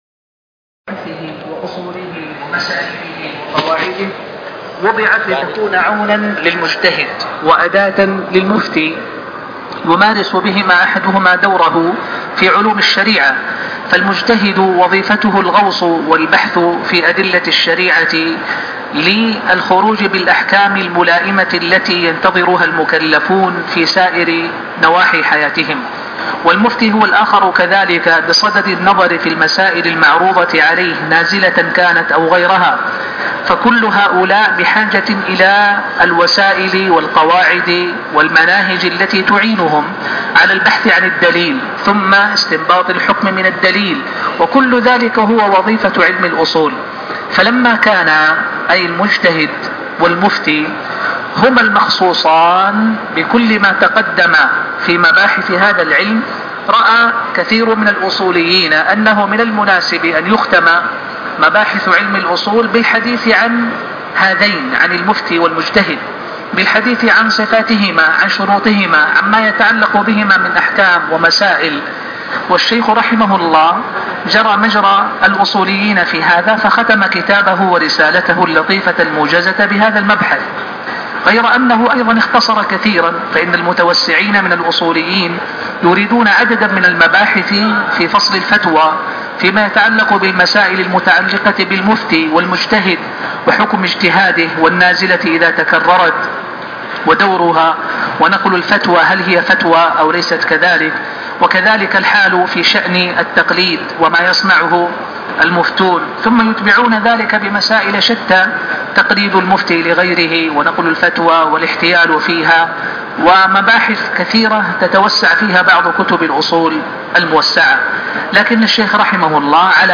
ألدرس الخامس عشر من شرح (الاصول من علم الاصول ) شرح أصول ابن عثيمين